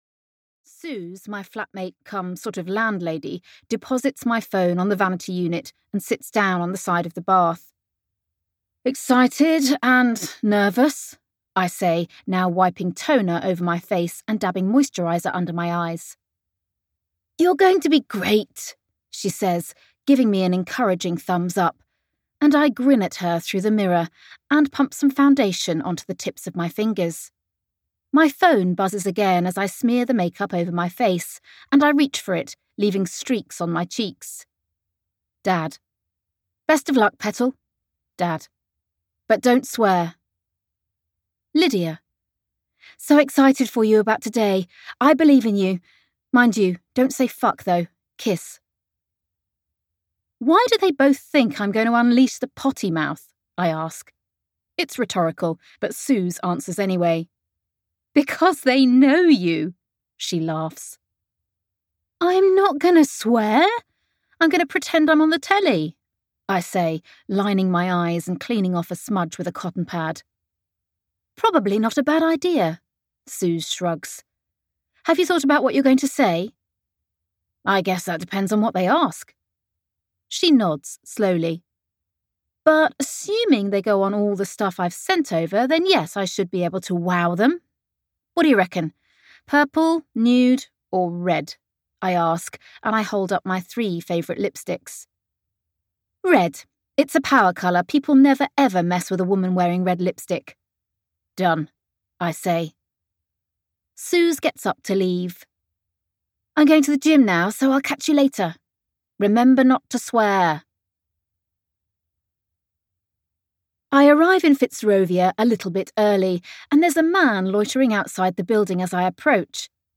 Audio knihaSwipe Right (EN)
Ukázka z knihy